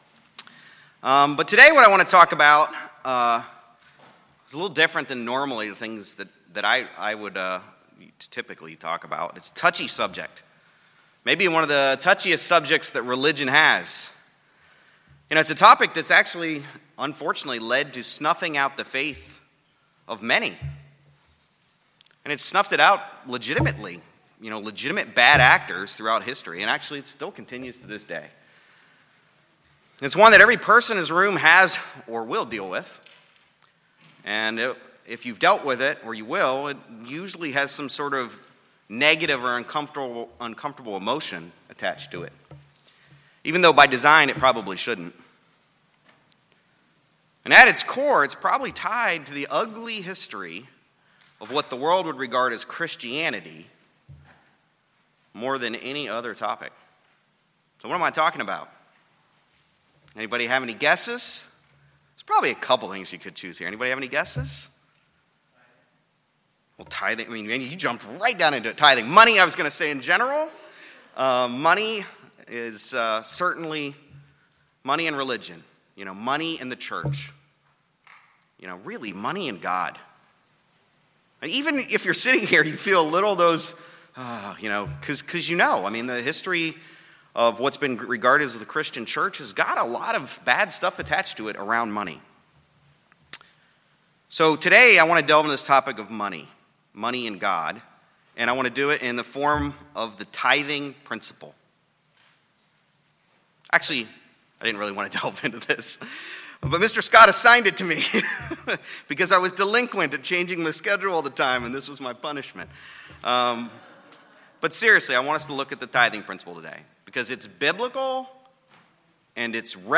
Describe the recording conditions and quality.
Given in Greensboro, NC Raleigh, NC